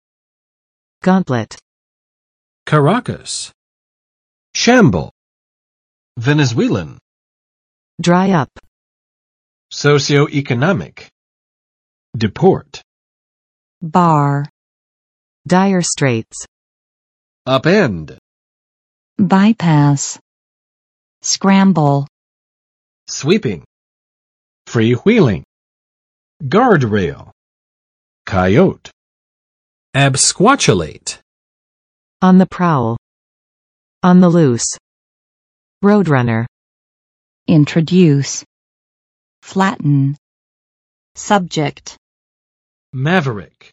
[ˋgɑntlɪt] n. 长手套; 防护手套
gauntlet.mp3